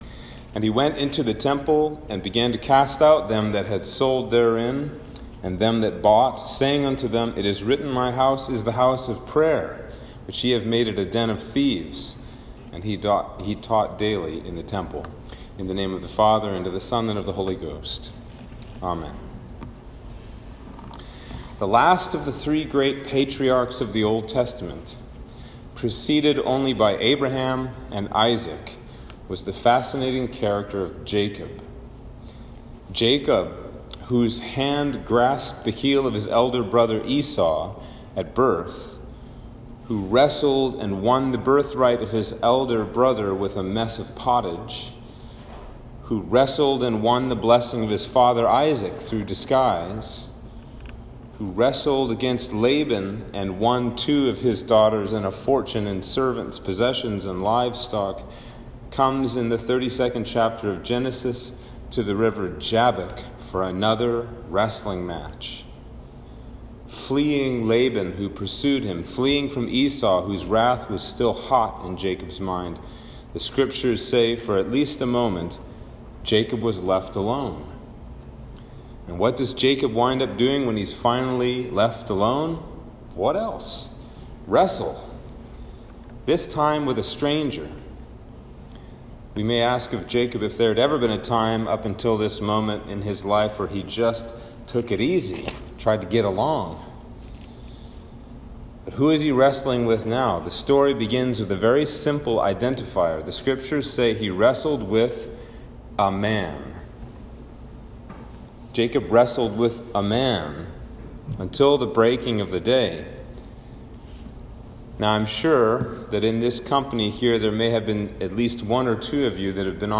Sermon for Trinity 10